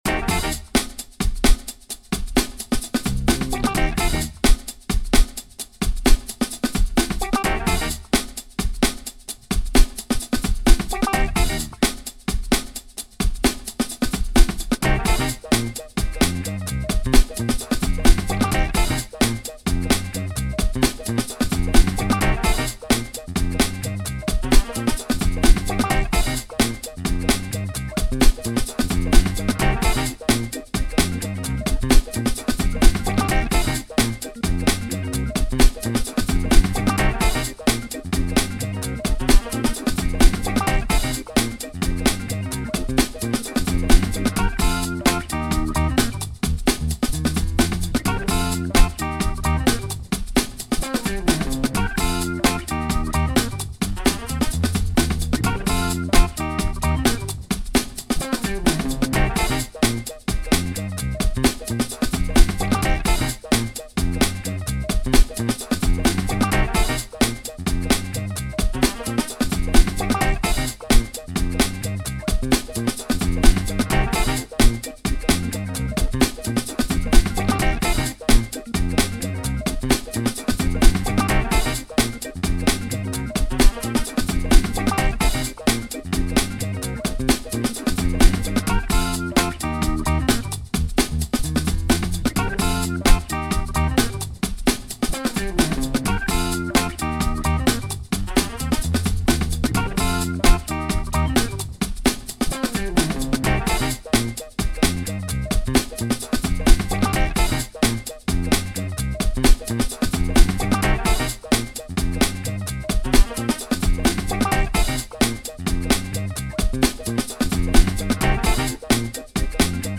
60s Soul, Soul
C minor